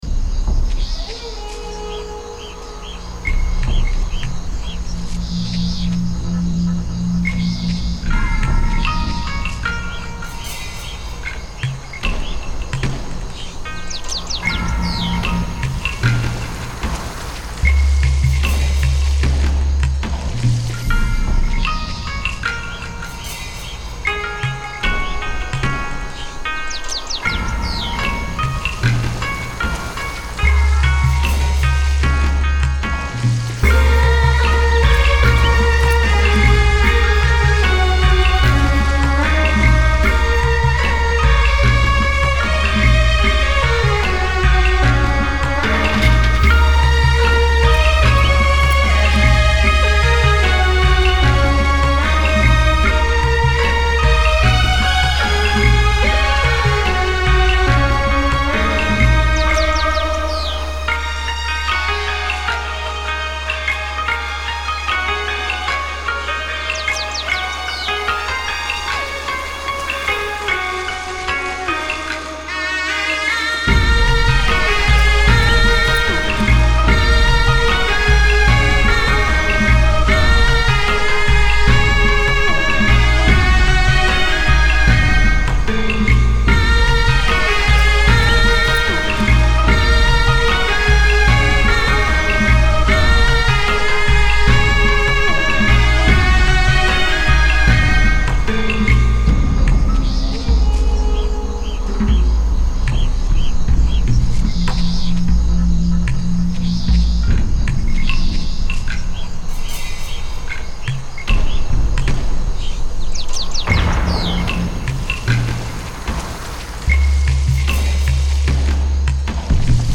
.: oriental / asian / african:.